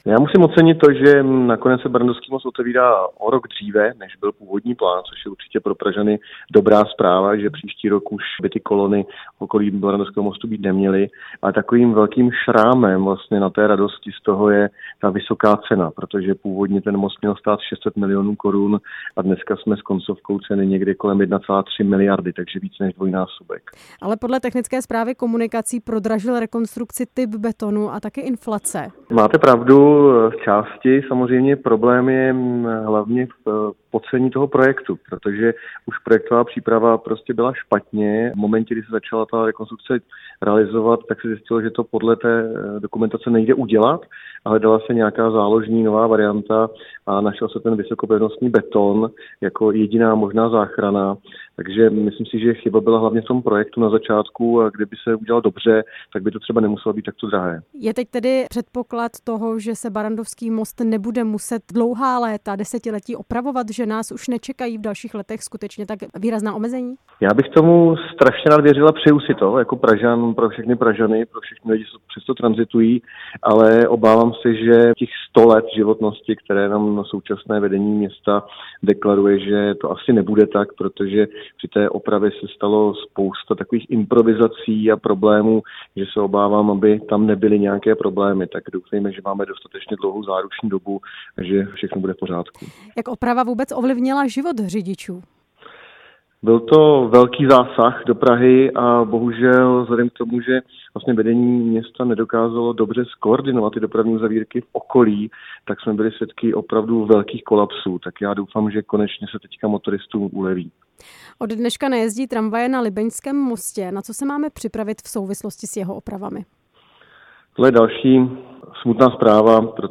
Jak dopadla a jak ovlivnila život řidičů? Hostem vysílání Radia Prostor byl Ondřej Prokop, pražský zastupitel a předseda hnutí ANO v Praze.